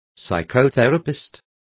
Complete with pronunciation of the translation of psychotherapists.